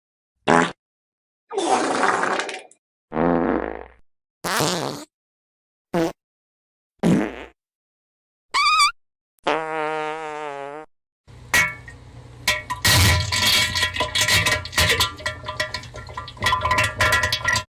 fart1.mp3